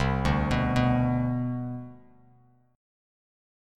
CM7sus2 chord